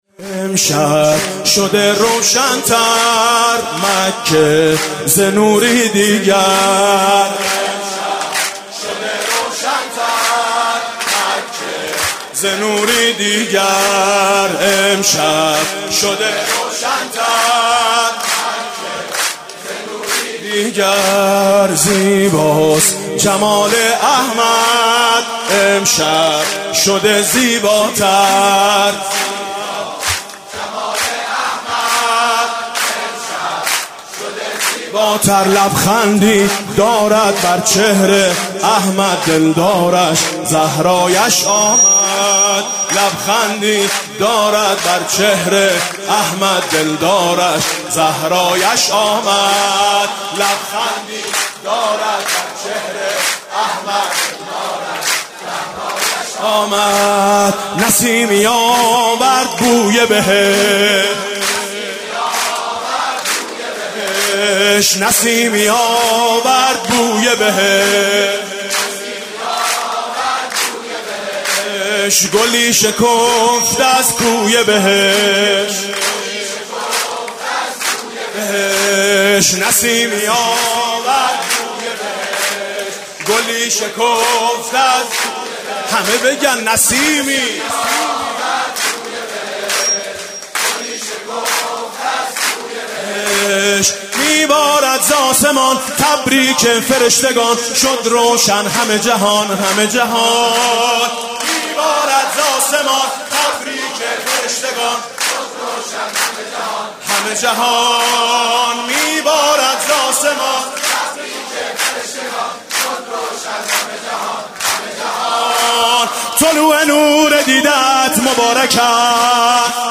سرود: گل محمدی من خوش آمدی